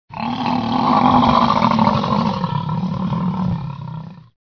Звуки бизона
Голодный бизон ревет, оглашая округу рыком